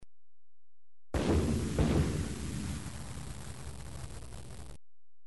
دانلود صدای بمب و موشک 4 از ساعد نیوز با لینک مستقیم و کیفیت بالا
جلوه های صوتی